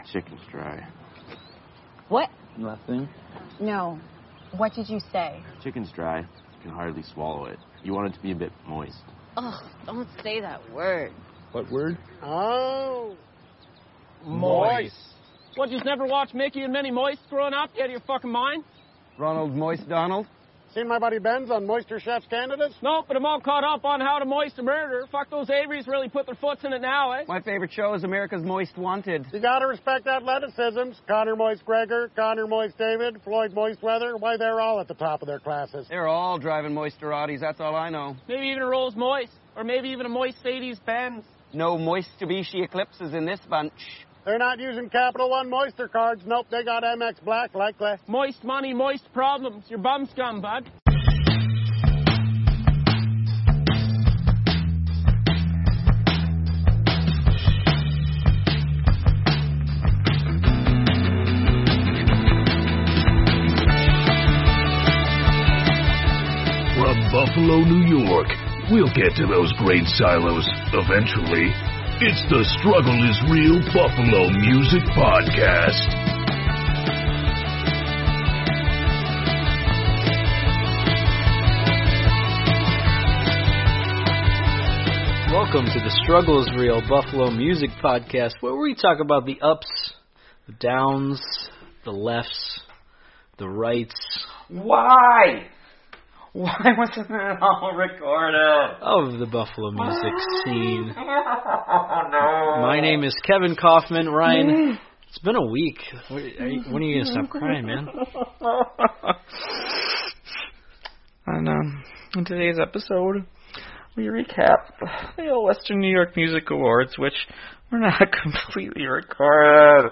We also play music from some of the winners (before running out of time)